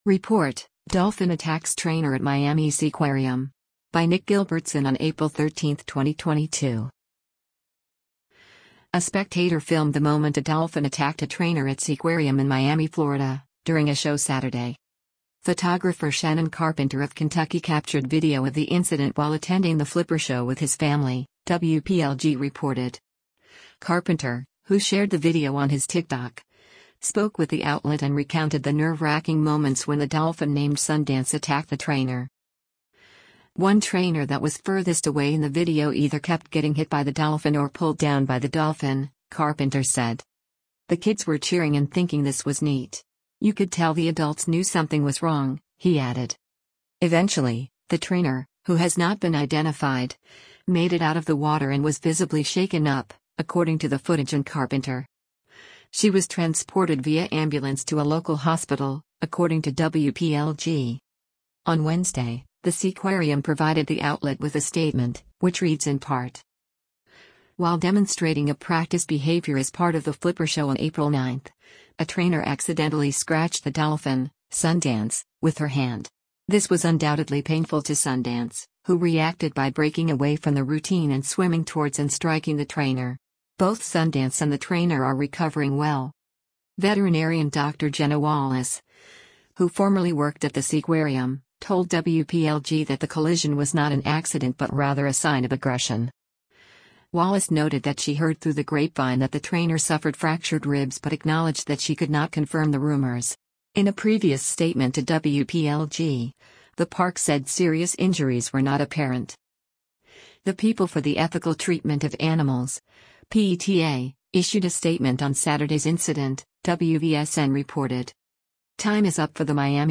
A spectator filmed the moment a dolphin attacked a trainer at Seaquarium in Miami, Florida, during a show Saturday.